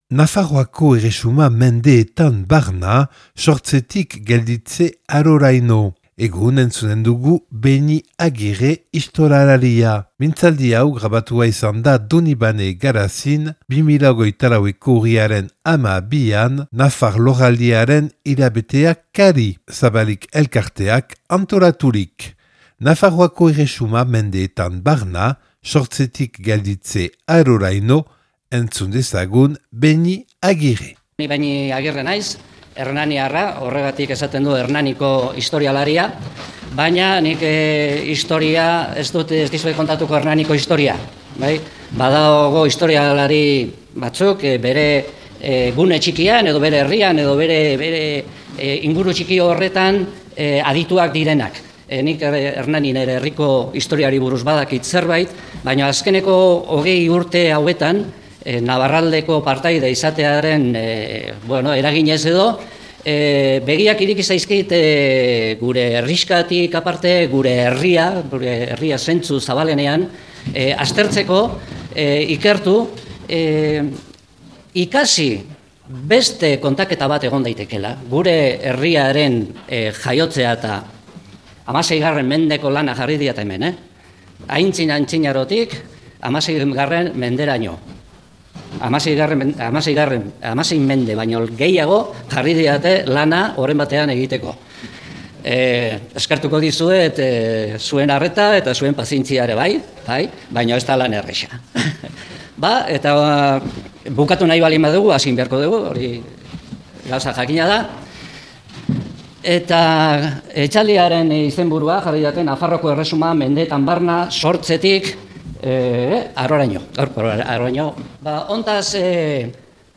(Donibane Garaziko Herriko Etxean grabatua 2024. Urriaren 12an Nafar Lorialdiaren hilabetea kari – Zabalik elkarteak antolaturik).